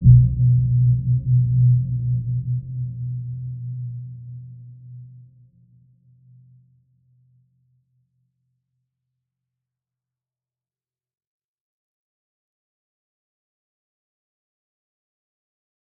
Dark-Soft-Impact-B2-mf.wav